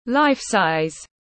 To như vật thật tiếng anh gọi là life-size, phiên âm tiếng anh đọc là /ˈlaɪf.saɪz/ .